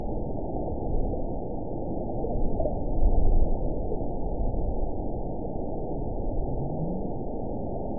event 920334 date 03/17/24 time 00:57:37 GMT (1 year, 8 months ago) score 9.62 location TSS-AB01 detected by nrw target species NRW annotations +NRW Spectrogram: Frequency (kHz) vs. Time (s) audio not available .wav